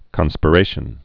(kŏnspə-rāshən)